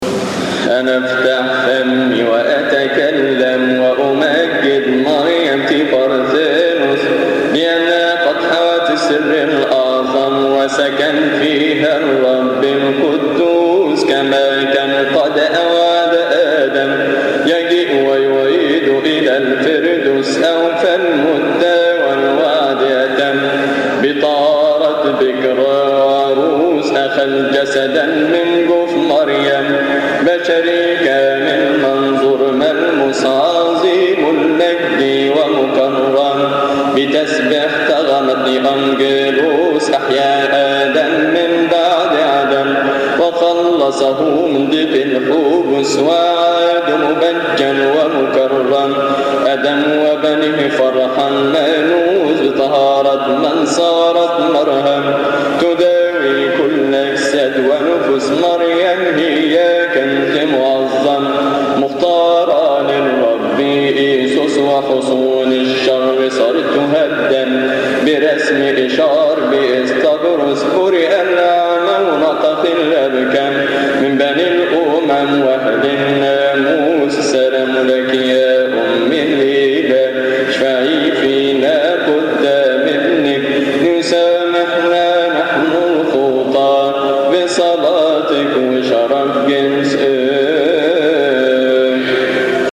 التفسير الأول للبردنوهي لثيؤطوكية السبت يصلي في تسبحة عشية أحاد شهر كيهك